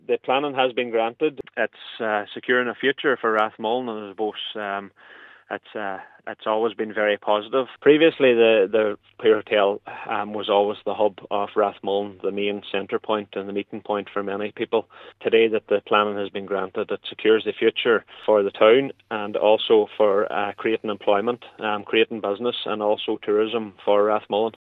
Cllr Pauric McGarvey says it’s a significant development…………..